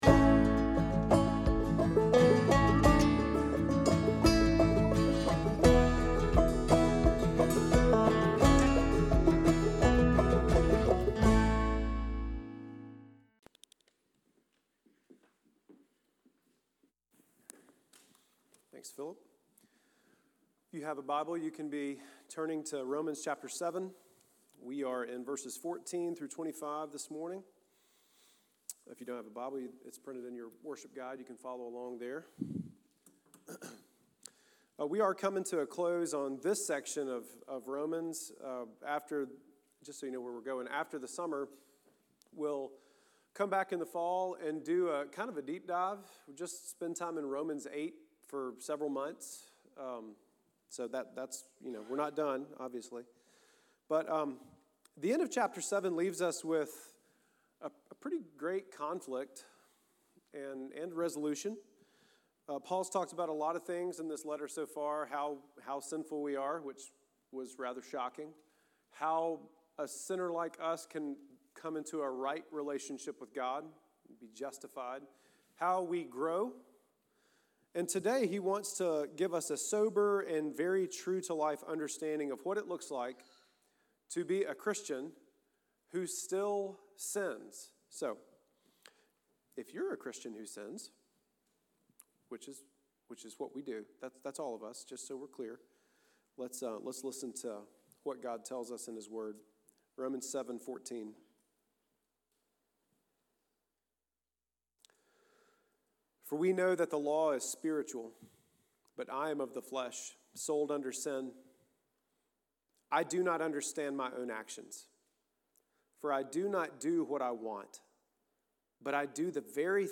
Sermon-for-51825-with-music.mp3